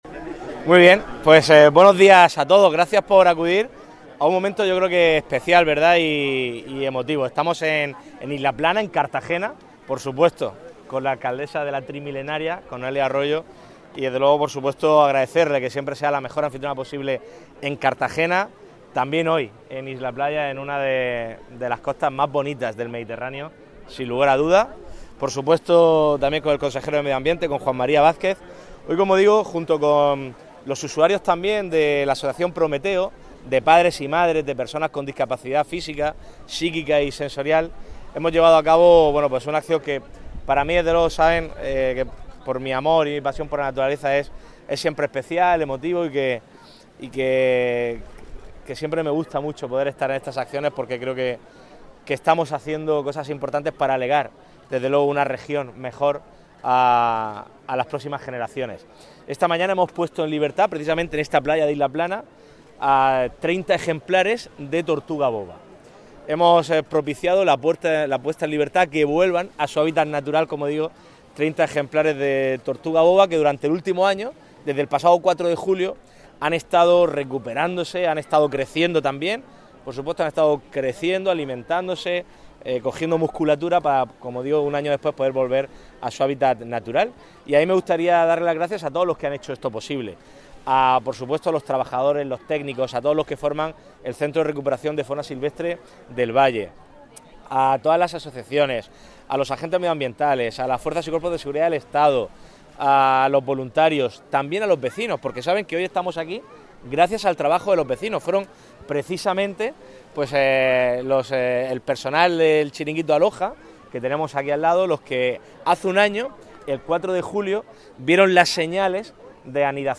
Enlace a Declaraciones de Fernando López Miras y Noelia Arroyo